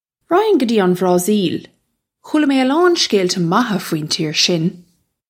Pronunciation for how to say
Rakh-hin guh jee on Vra-seel - khoola may uh lawn shkayl-tuh moh-ha fween cheer shin
This is an approximate phonetic pronunciation of the phrase.